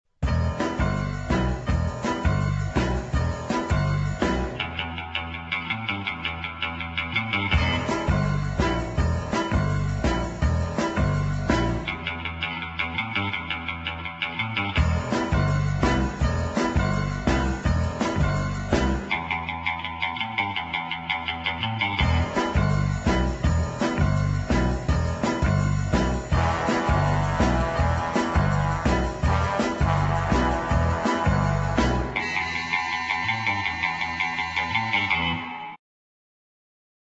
1968 exciting medium instr.